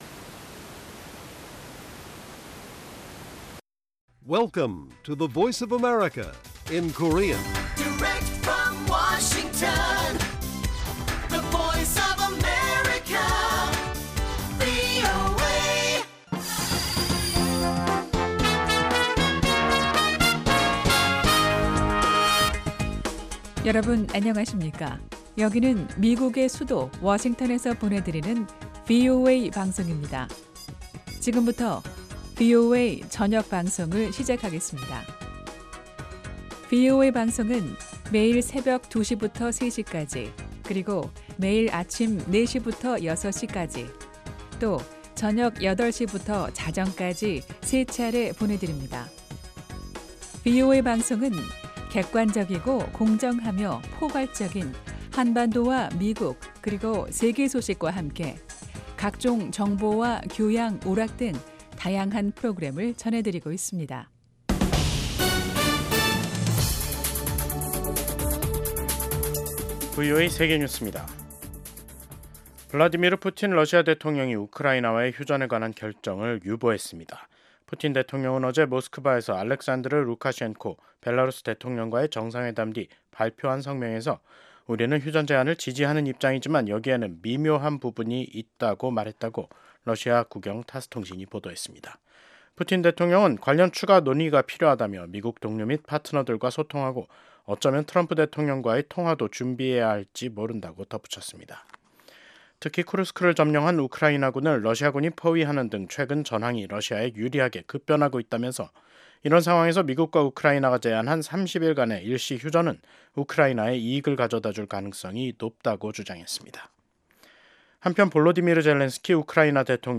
VOA 한국어 간판 뉴스 프로그램 '뉴스 투데이', 2025년 3월 14일 1부 방송입니다. 도널드 트럼프 미국 대통령이 또다시 북한을 ‘뉴클리어 파워’ 즉 ‘핵 국가’로 지칭했습니다. 일본 주재 미국 대사 지명자가 미한일 3국 협력 강화가 필수적이라며 지속적인 노력이 필요하다고 강조했습니다. 지난 10년 동안 북한 선박 8척이 중국해역 등에서 침몰한 것으로 나타났습니다.